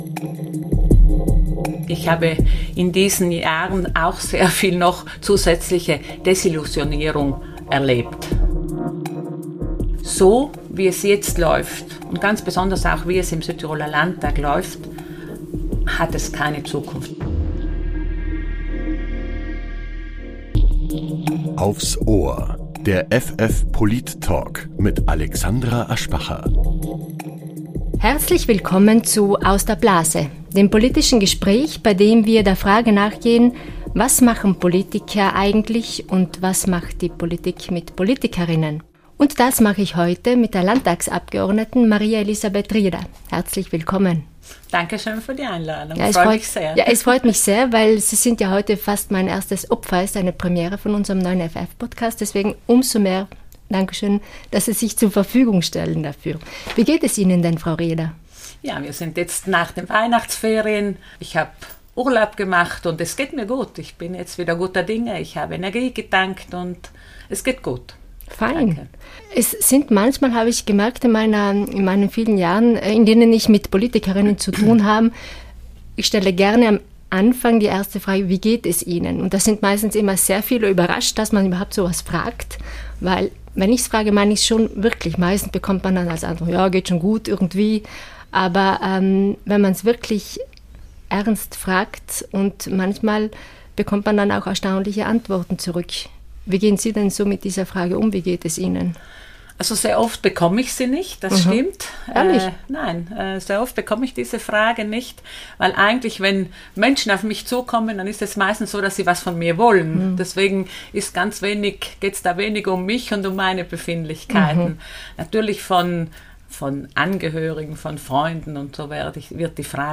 Der Polit-Talk
Gast in Folge 1 ist die Landtagsabgeordnete Maria Elisabeth Rieder